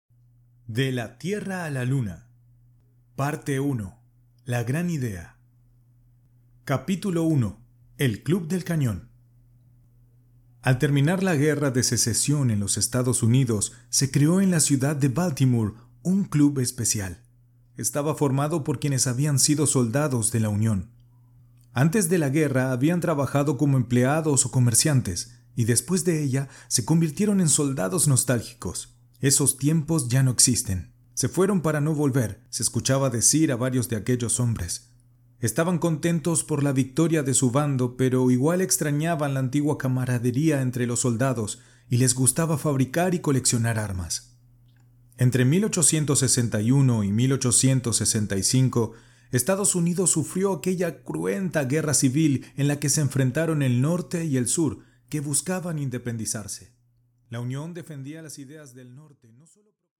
Muestra de audiolibro